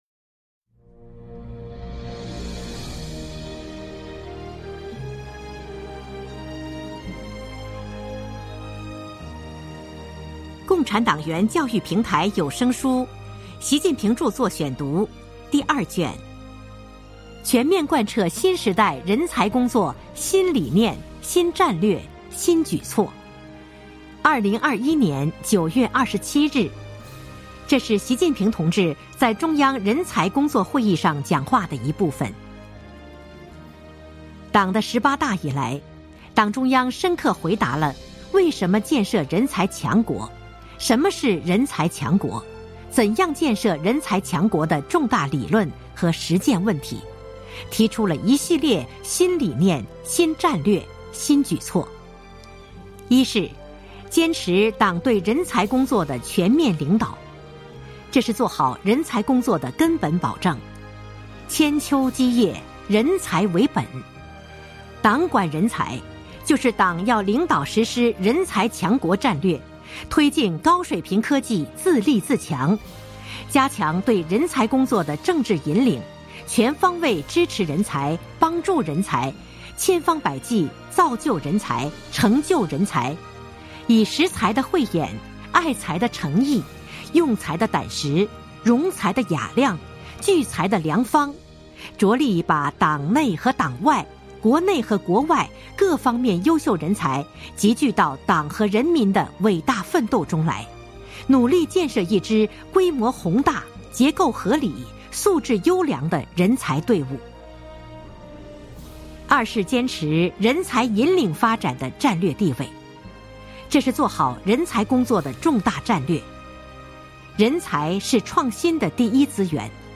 主题教育有声书 《习近平著作选读》第二卷（73）.mp3